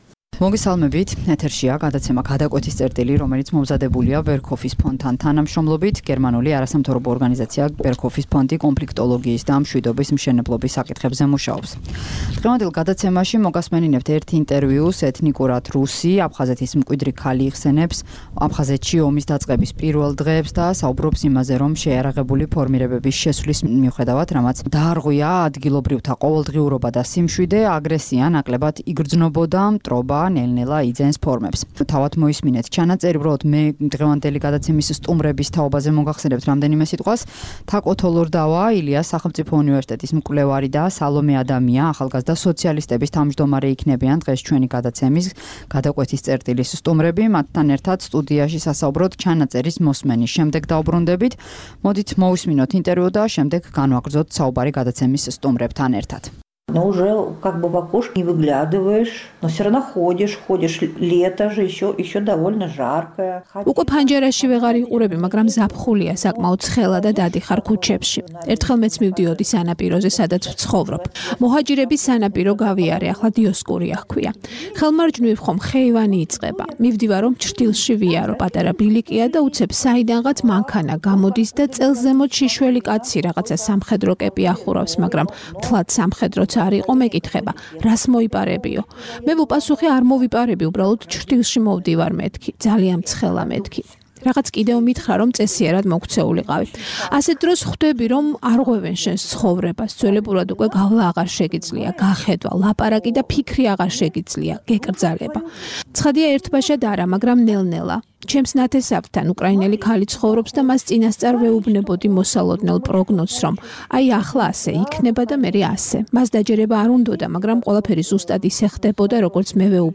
დღევანდელ გადაცემაში შემოგთავაზებთ ერთ ინტერვიუს. ეთნიკურად რუსი აფზახეთის მკიდრი ქალი იხსენებს აფხაზეთის ომის დაწყების პირველ დღეებს და ამბობს, რომ, შეიარაღებული ფორმირებების შესვლის მიუხედავად, რამაც დაარღვია ადგილობრივთა ყოველდღიური სიმშვიდე, აგრესია ნაკლებად იგრძნობოდა.